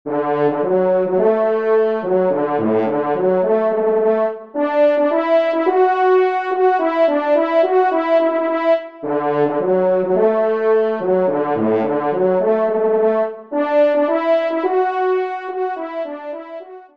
Genre :  Divertissement pour Trompes ou Cors
1e Trompe